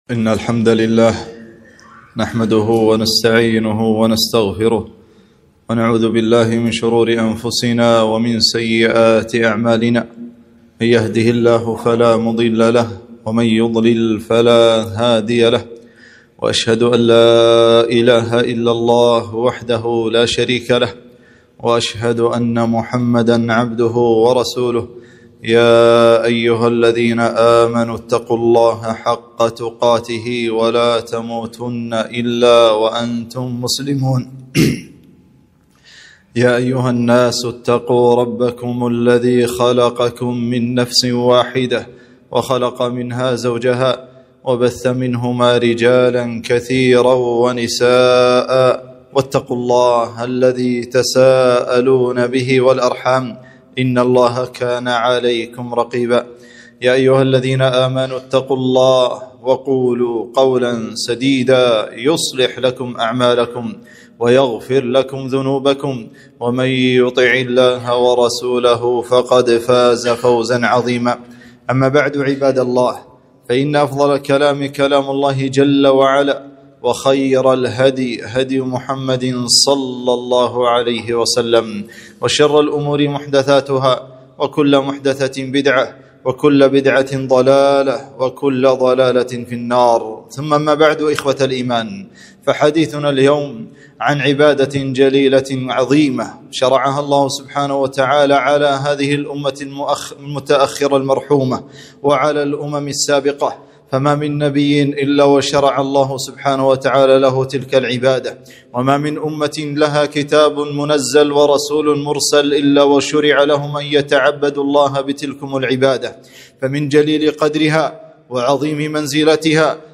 خطبة - إضاعة الصلاة وخطرها